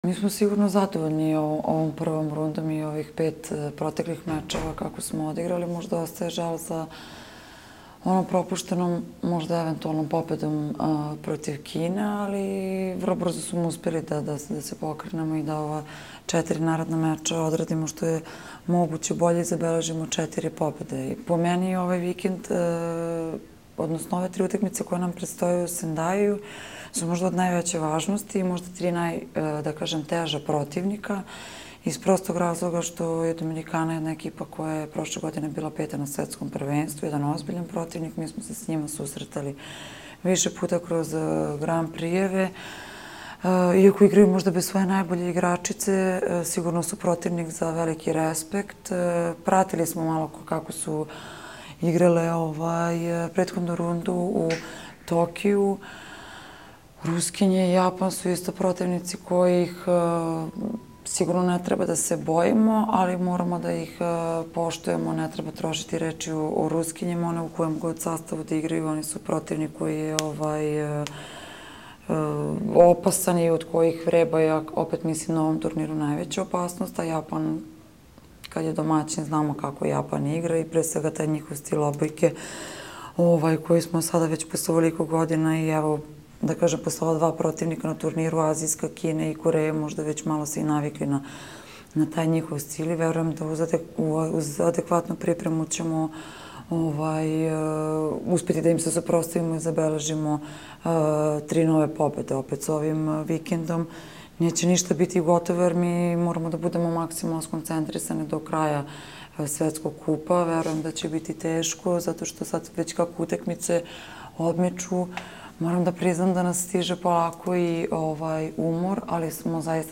IZJAVA MAJE OGNJENOVIĆ, KAPITENA SRBIJE